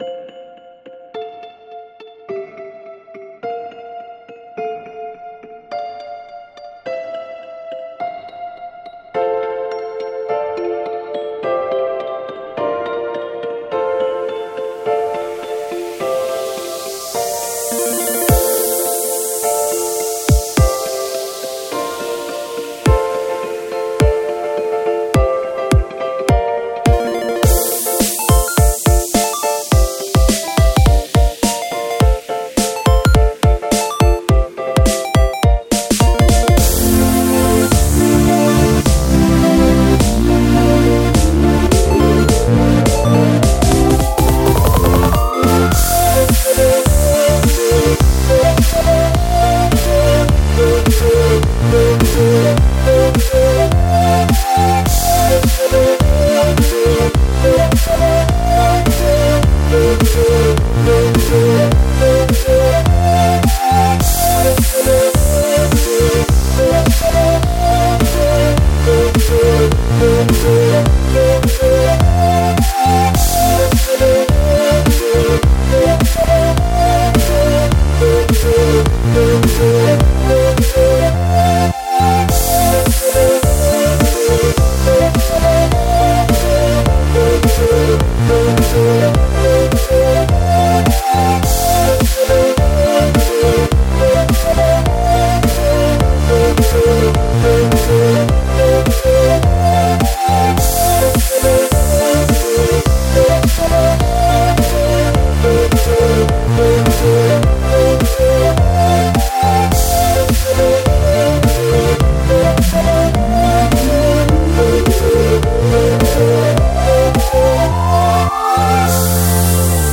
イントロは夏至をイメージしています。